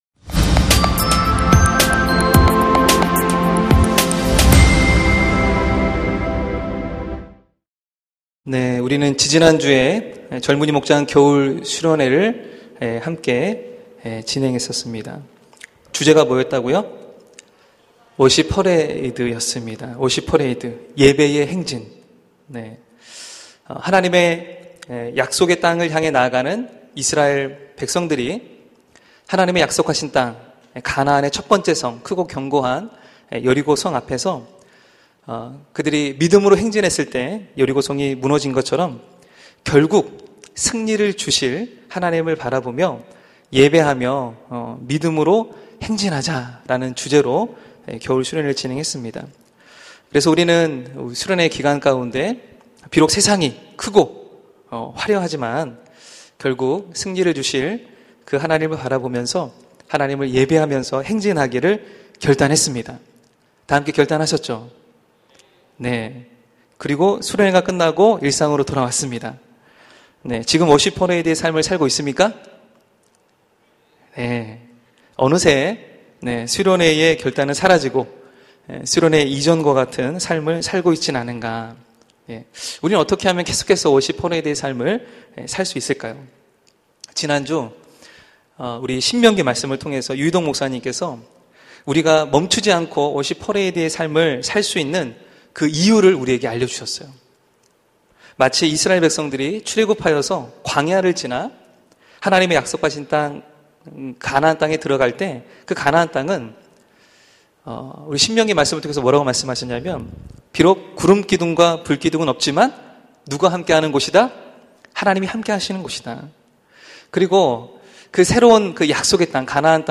설교 : 파워웬즈데이